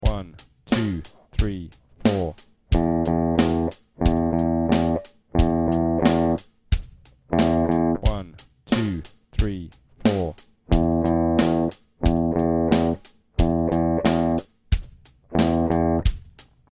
Grade 2 Rhythmic Recall Audio Samples
These are a 2 bar rhythm using the E notes on the 6th string of the guitar.